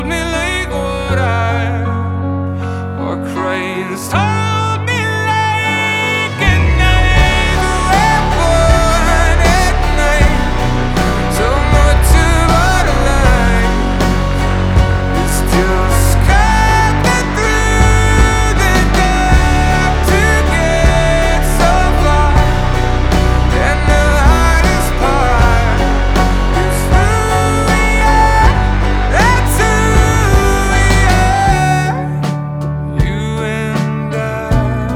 2023-08-18 Жанр: Альтернатива Длительность